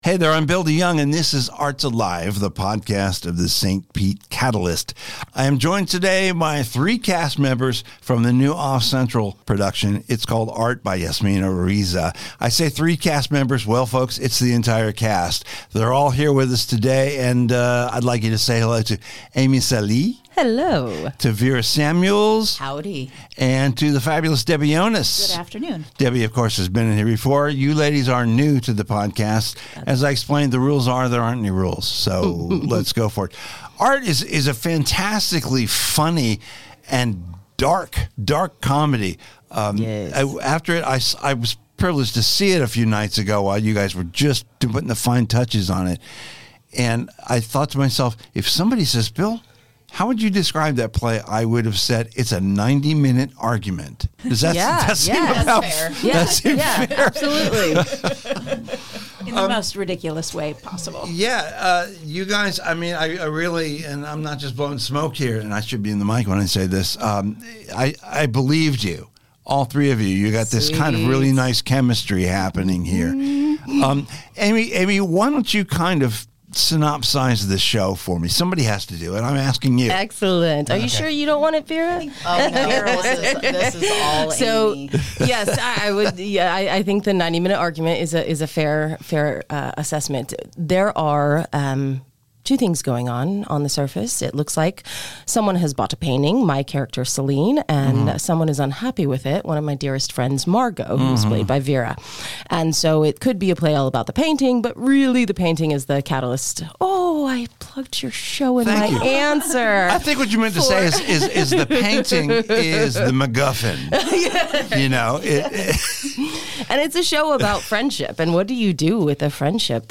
On this Arts Alive! podcast we welcome the entire cast of the Off-Central production, three women who portray three women who simply can’t agree on an interpretation of art.